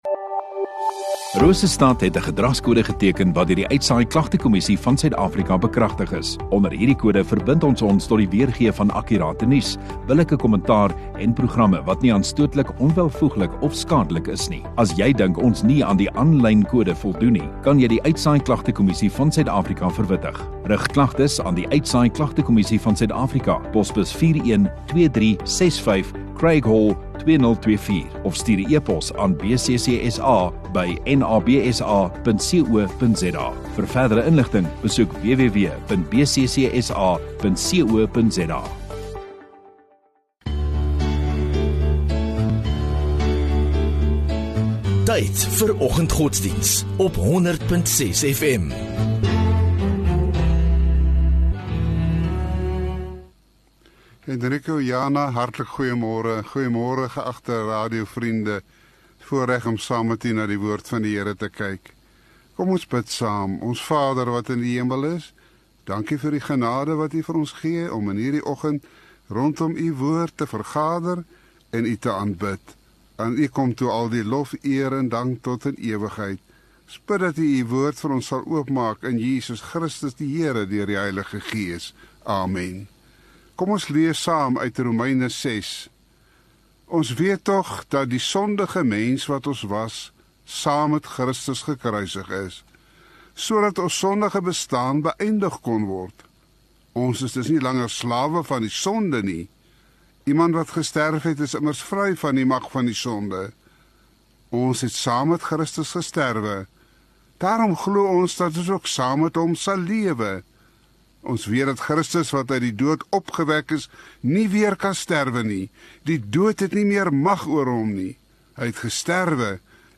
24 Mar Maandag Oggenddiens